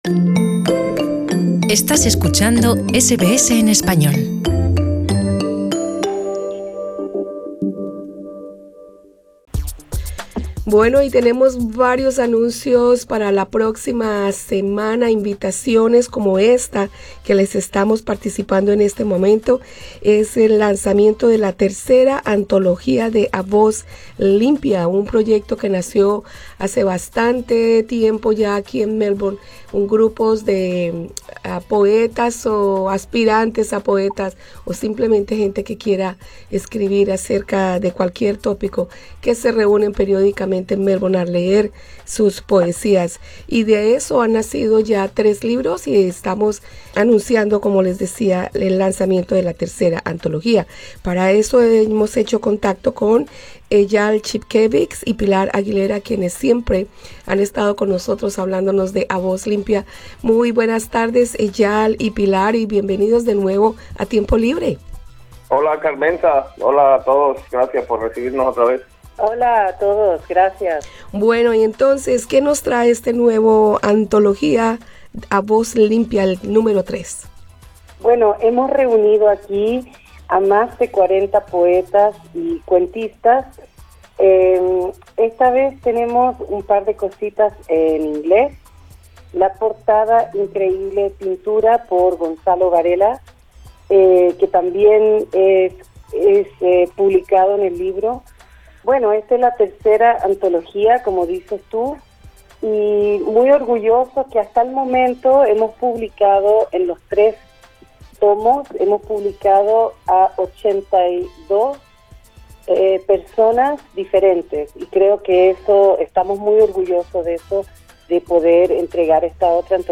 Escucha arriba en nuestro podcast la entrevista.